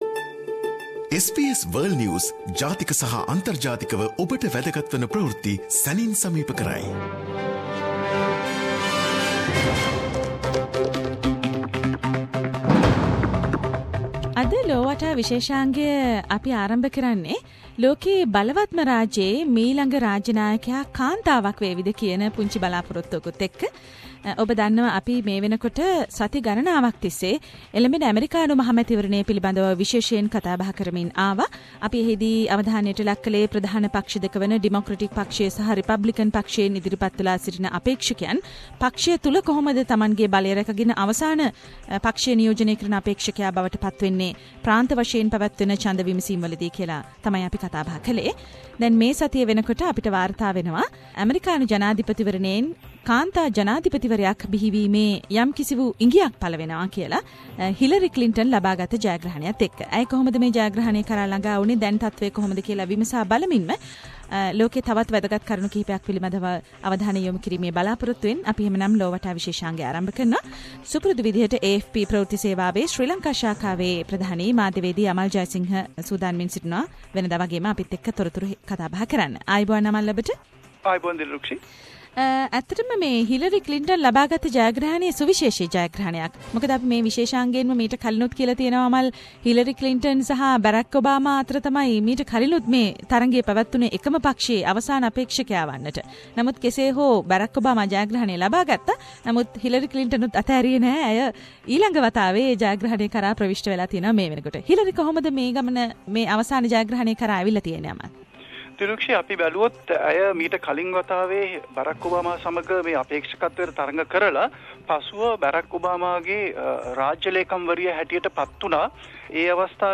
SBS සිංහල සේවයේ සතිපතා ලෝක ප්රවෘති සමාලෝචනය "ලොව වටා" තුලින් මෙවර ප්රමුඛව සාකච්චාවට ලක්වනුයේ ඇමෙරිකානු ජනාධිපතිවරණය පිළිබඳවයි. ඊට අමතරව, ඉන්දීය අගමැති නරේන්ද්ර මෝඩි ගේ ඇමෙරිකානු සංචාරය, ඊශ්රායල - පලස්තීන අලුත්ම ගැටුම, ඉජිප්ප්තු ගුවන් යානයක ඇතිවුණු බෝම්බ බිය ඇතුළු කාරනායි.